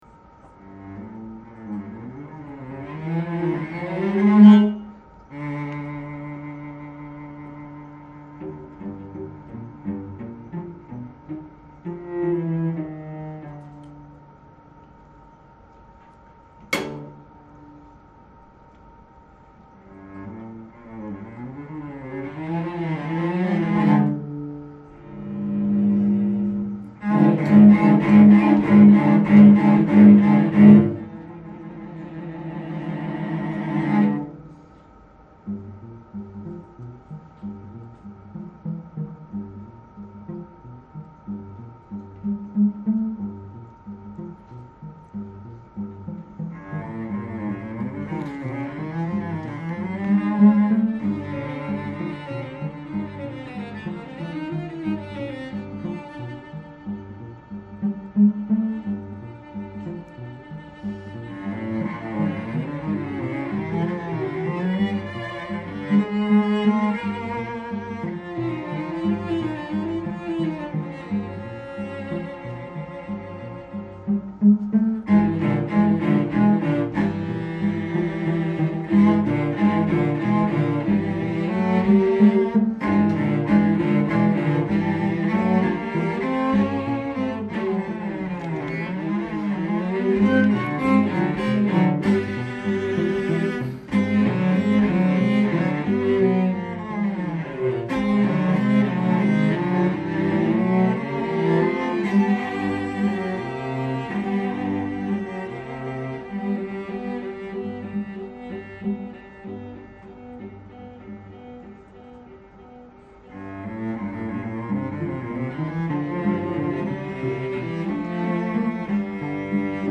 for three cellos